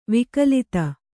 ♪ vikalita